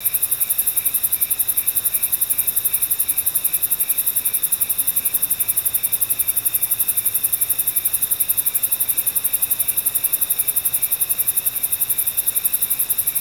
Index of /90_sSampleCDs/E-MU Producer Series Vol. 3 – Hollywood Sound Effects/Ambient Sounds/Night Ambience
NIGHT AMB01R.wav